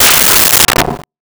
Metal Strike 02
Metal Strike 02.wav